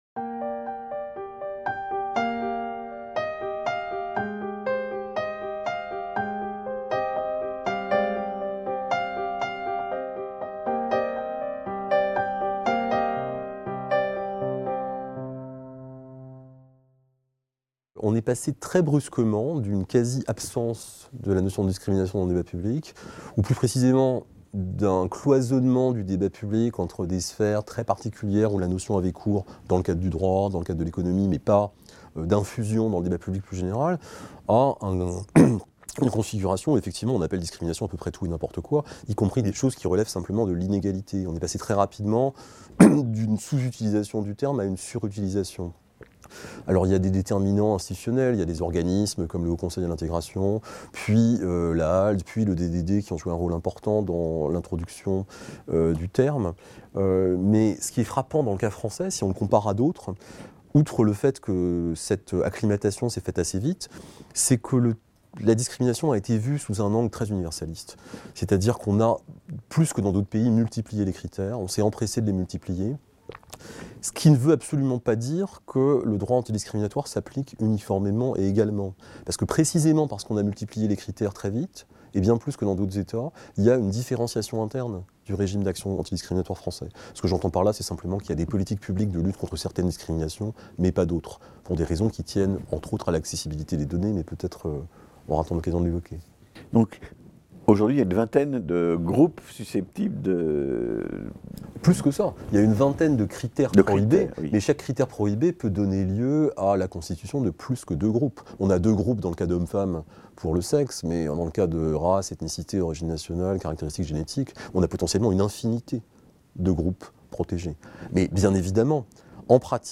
Discriminations - Un entretien